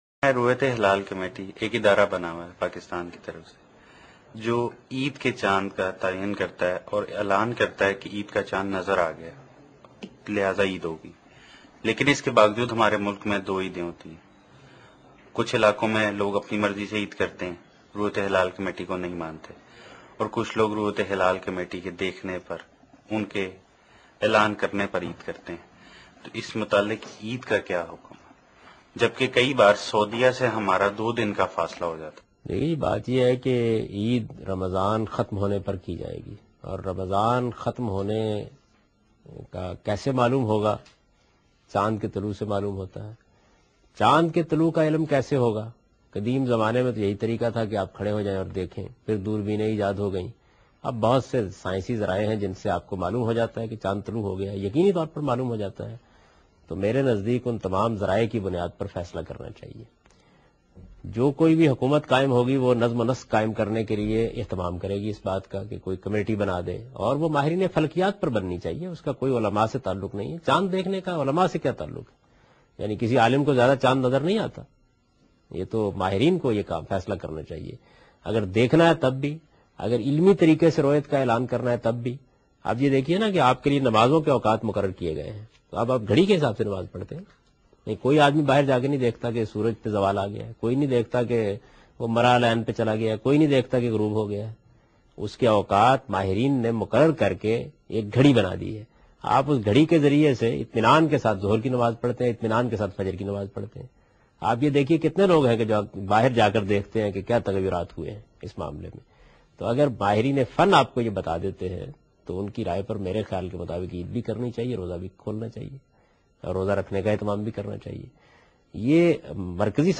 Category: Reflections / Questions_Answers /
In this video Javed Ahmad Ghamidi answers a question regarding "Moon Sighting and Moon of Eid"